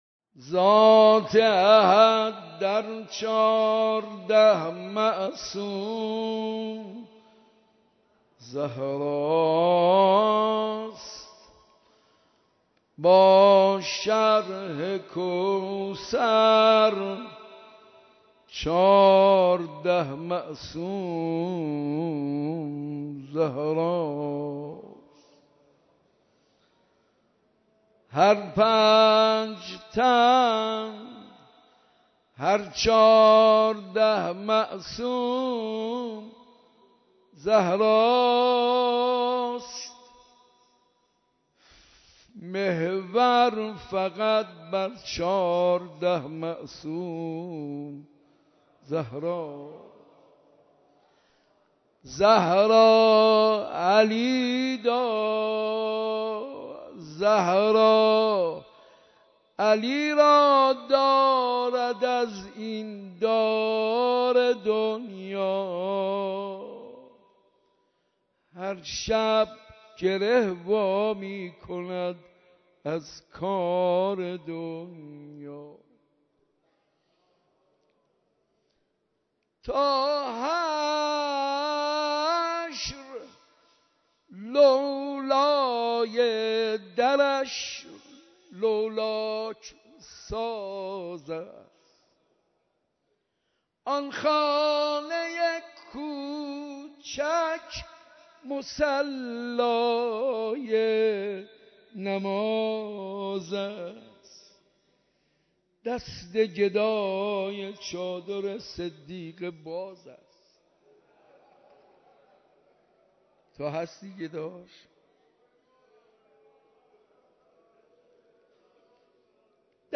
اولین شب مراسم عزاداری شهادت حضرت فاطمه‌زهرا سلام‌الله‌علیها
مداحی